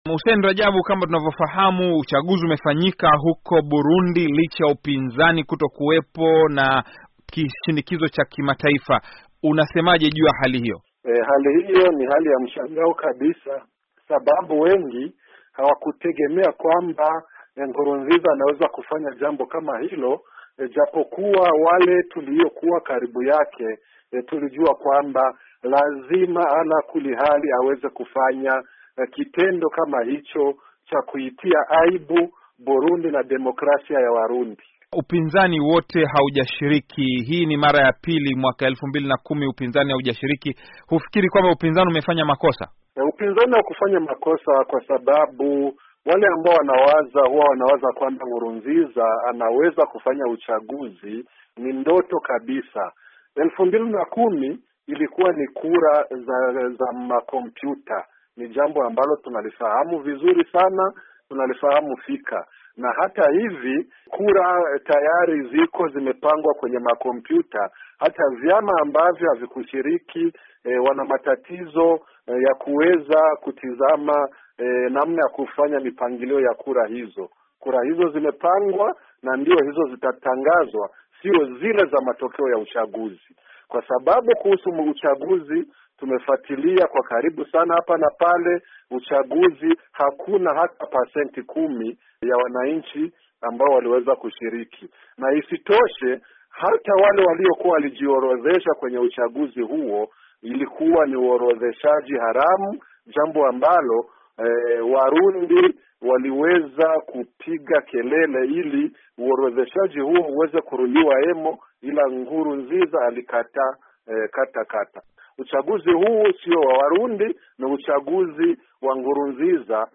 Mahojiano na Hussein Radjabu